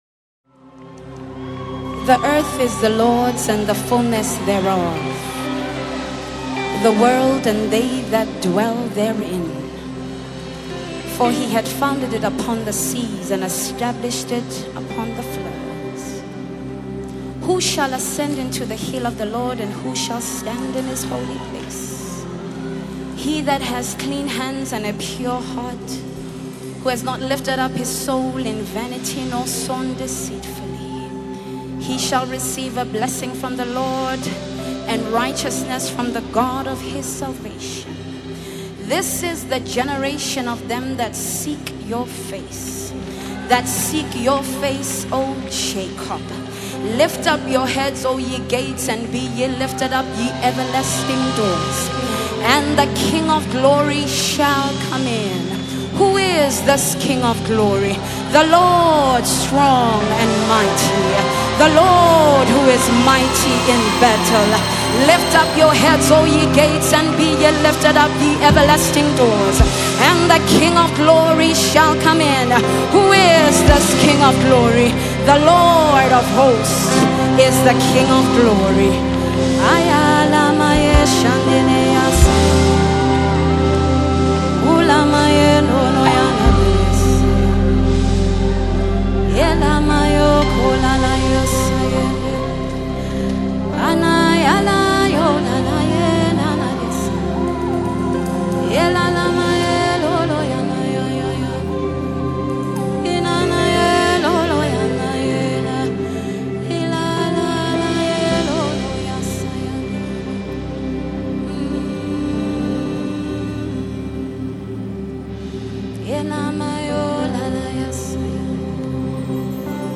Contemporary Christian music singer